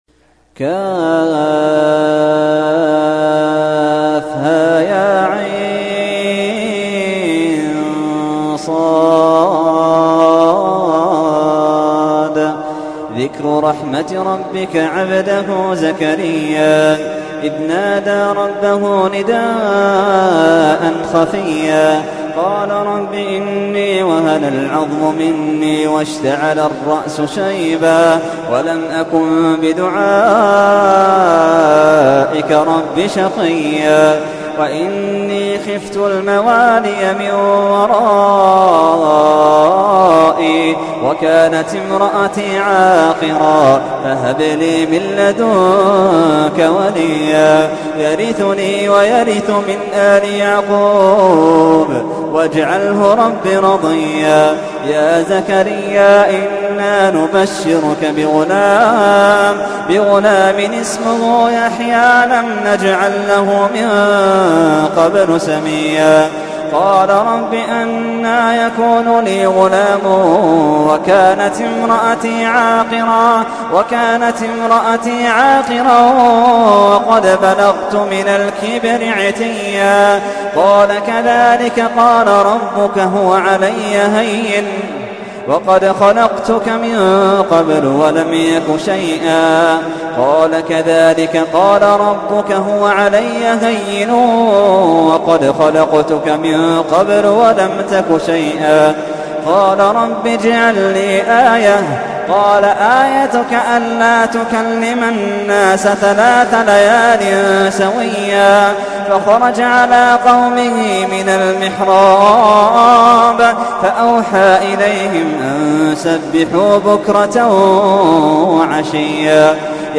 تحميل : 19. سورة مريم / القارئ محمد اللحيدان / القرآن الكريم / موقع يا حسين